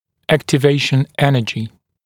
[ˌæktɪ’veɪʃn ‘enəʤɪ][ˌэкти’вэйшн ‘энэджи]энергия, возникающая при активации